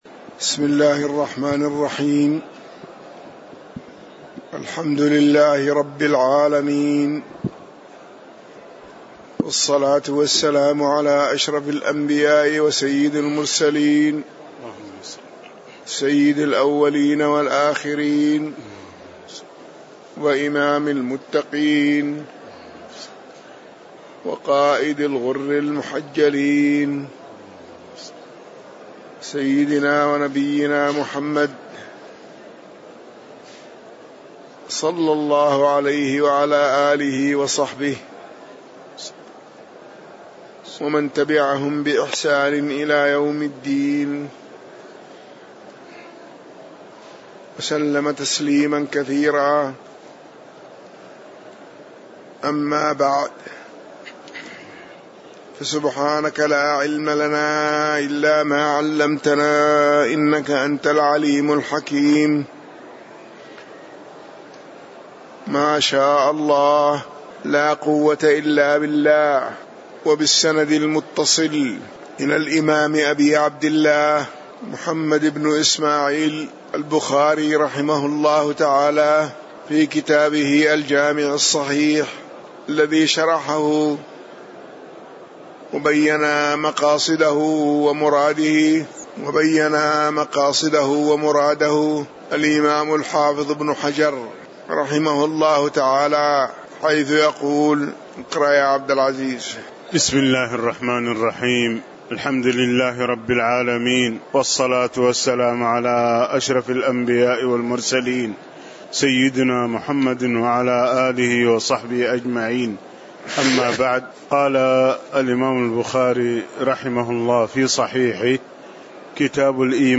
تاريخ النشر ٢٨ محرم ١٤٣٩ هـ المكان: المسجد النبوي الشيخ